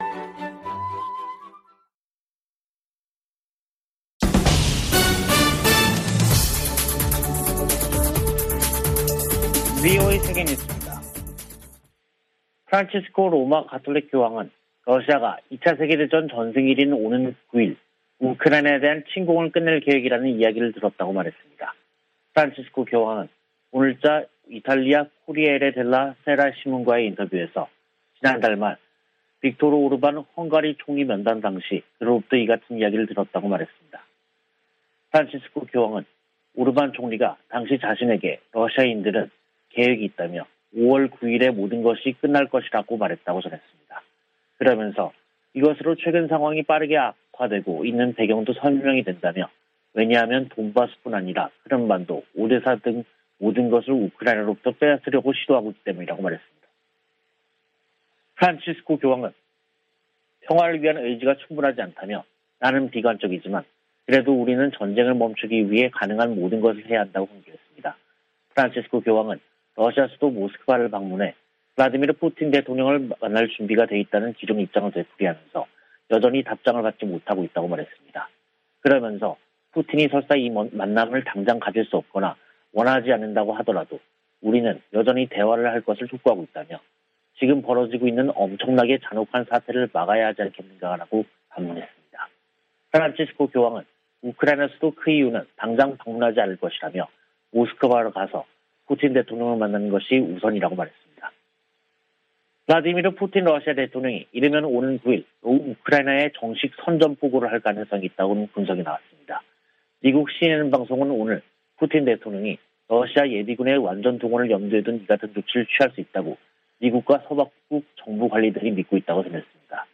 VOA 한국어 간판 뉴스 프로그램 '뉴스 투데이', 2022년 5월 3일 2부 방송입니다. 미 국무부는 북한 풍계리 핵실험장 복구 조짐에 대해 위험한 무기 프로그램에 대처할 것이라고 밝혔습니다. 한국의 대통령직 인수위원회는 북한 비핵화 추진을 국정과제로 명시했습니다. 한국과 중국의 북 핵 수석대표들은 북한의 핵실험 재개 움직임 등에 대해 상황 악화를 막기 위해 공동으로 노력하기로 다짐했습니다.